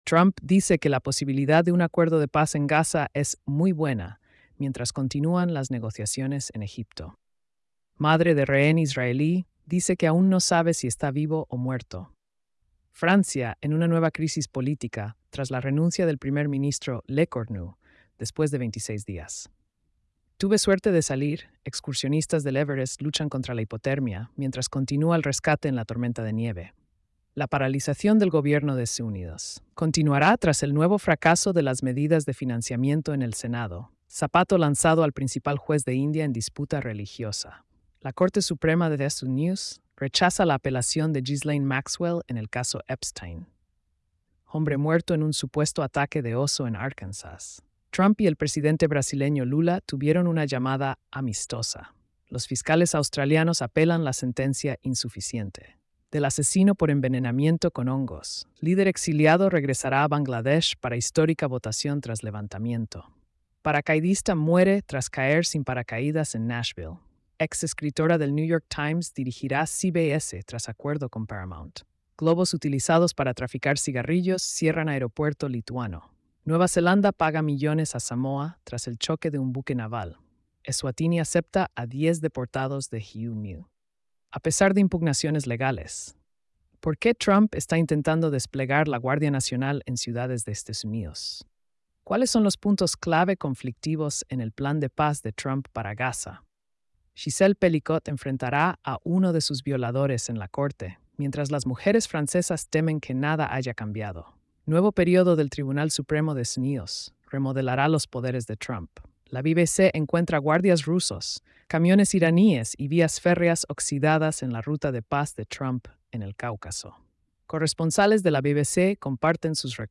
🎧 Resumen de noticias diarias.